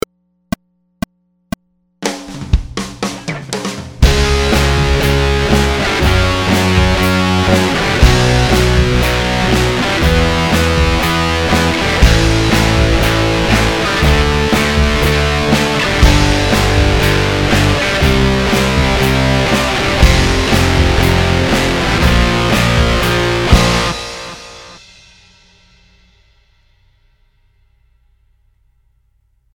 Power Chord Drill I play along track
Power-Chord-Drill-I.mp3